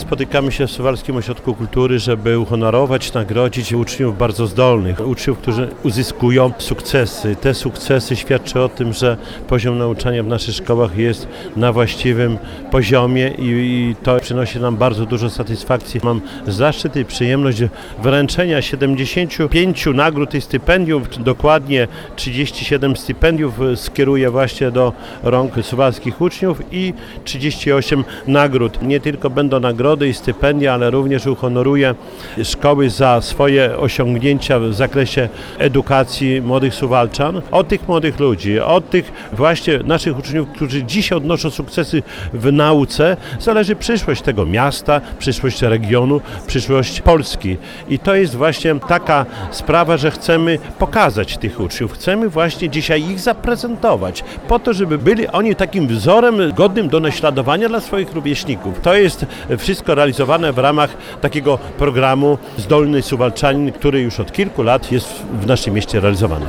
Gala Ucznia Zdolnego odbyła się w środę (17.10.18) w Suwalskim Ośrodku Kultury. Z tej okazji Czesław Renkiewicz, prezydent Suwałk, wręczył specjalne nagrody i wyróżnienia uczniom, którzy osiągnęli wysokie wyniki w sferze naukowej.
– To wyjątkowa chwila dla wszystkich uczniów, którzy każdego dnia dają z siebie wszystko i ciężko pracują na końcowy sukces. Jest to także dobra okazja do pokazania wszystkim, że nauka, to świetna inwestycja w dalszy rozwój młodego człowieka – powiedział Czesław Renkiewicz, prezydent Suwałk.